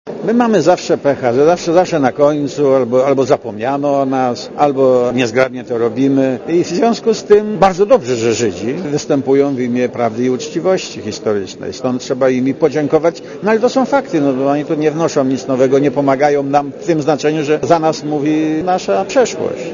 Mówi Lech Wałęsa